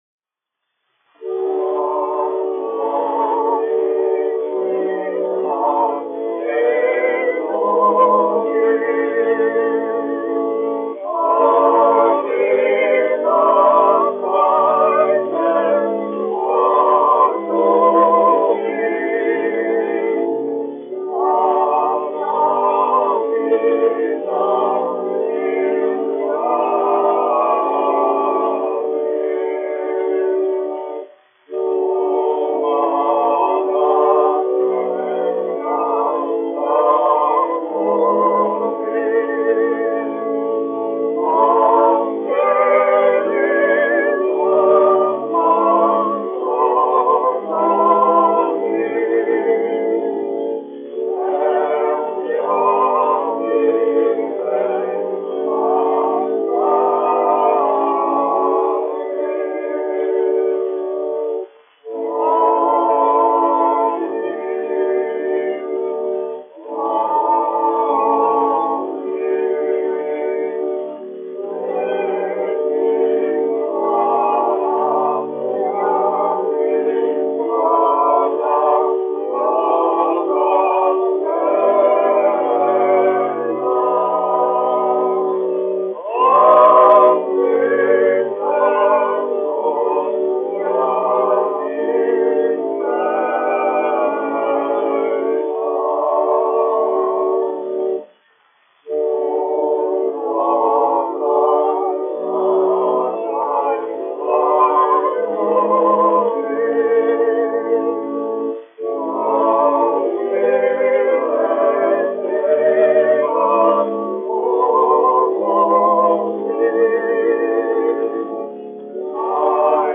1 skpl. : analogs, 78 apgr/min, mono ; 25 cm
Kori, garīgie (jauktie) ar ērģelēm
Korāļi
Skaņuplate